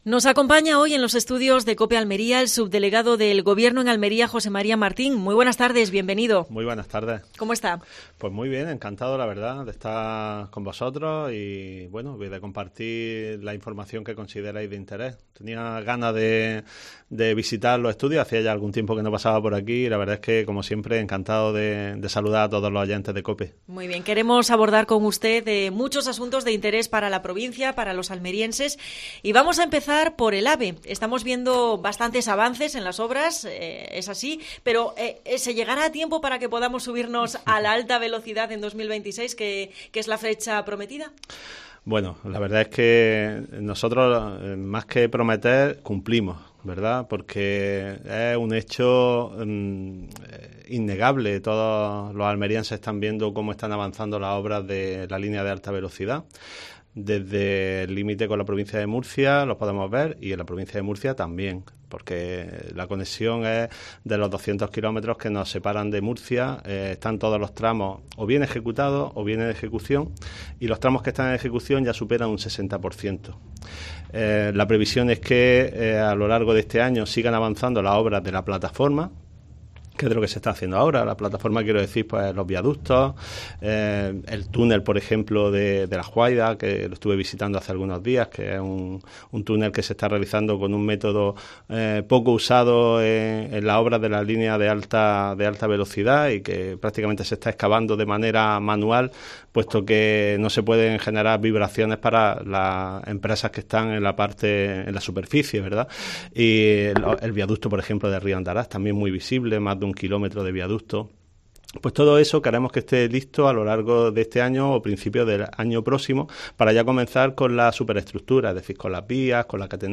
Entrevista al subdelegado del Gobierno en Almería, José María Martín